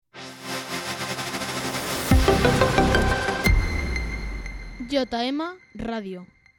Indiactiu de la ràdio